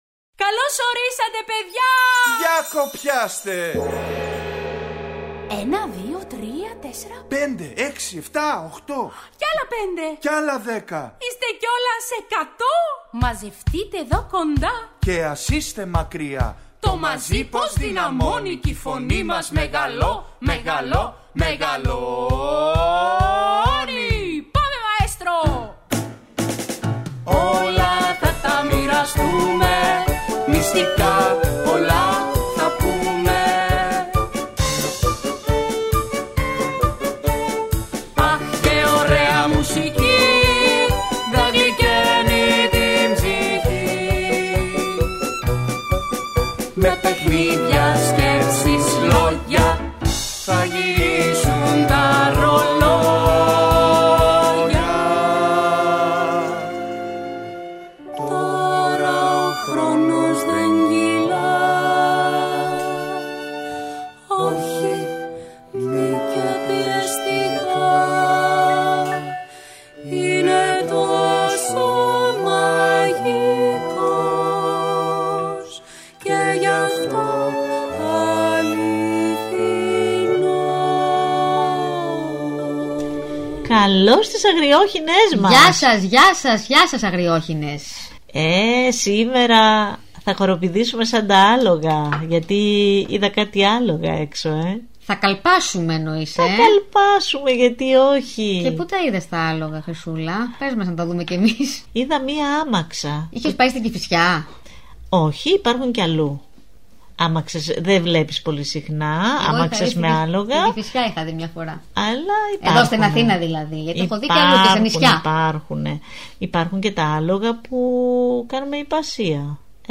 Ακούστε στην παιδική εκπομπή ‘’Οι Αγριόχηνες’’ το παραμύθι «Οι δυο ουρές» της Γιολάντας Τσορώνη–Γεωργιάδη.